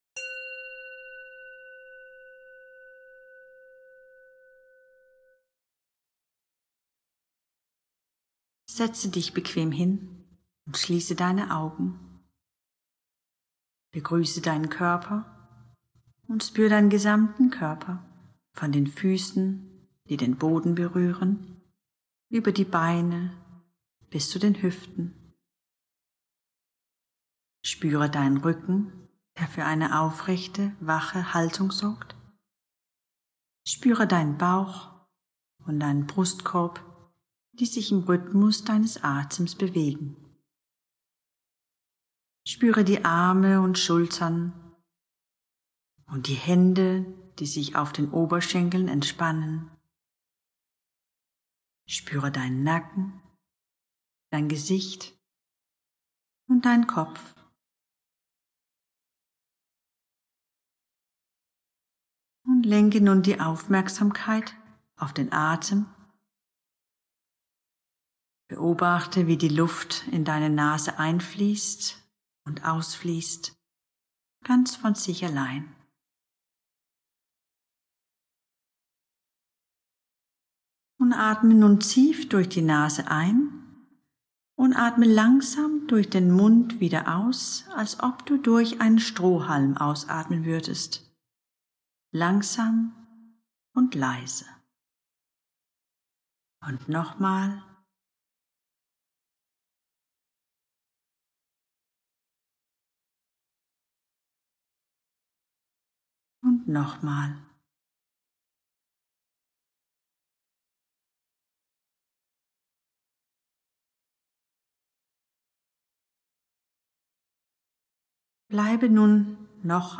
01-Stillmeditation - 10min.mp3